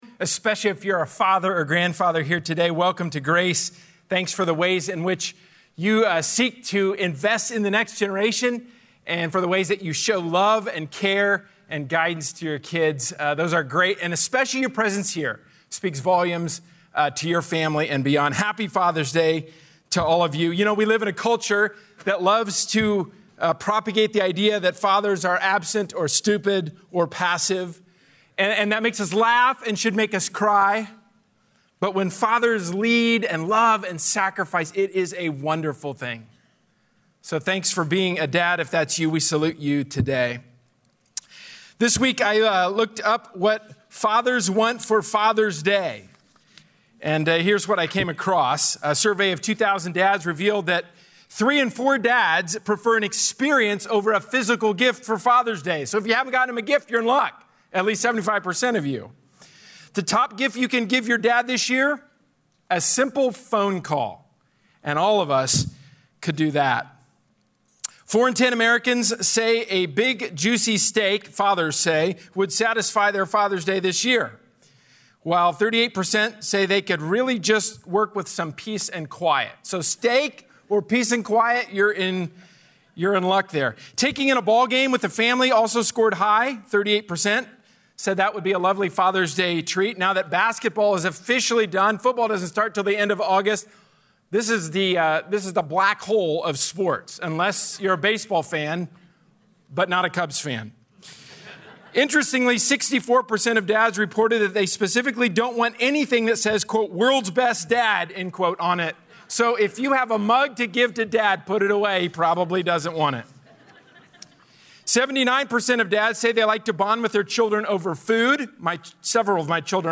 A sermon from the series "Standalone Sermons." Godly fathers are central to God's purpose. Submission to God's design is essential for godly fathers.